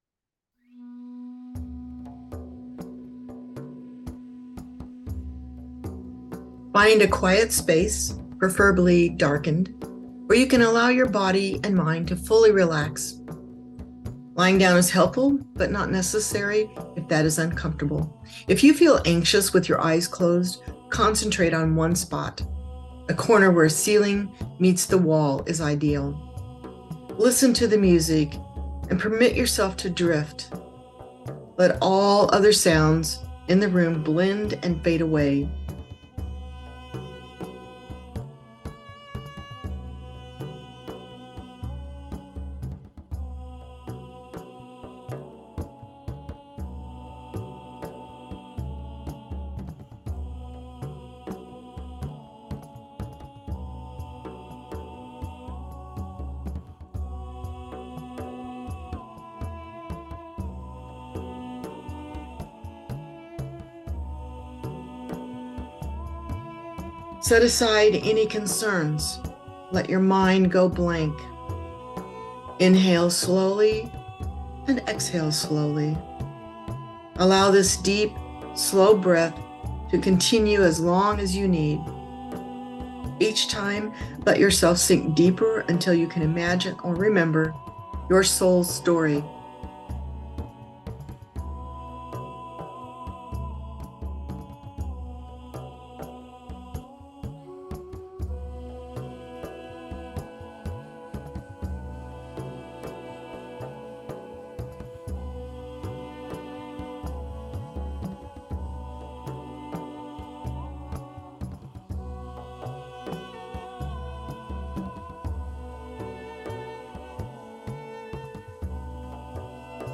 Ancestral Healing Meditation.mp3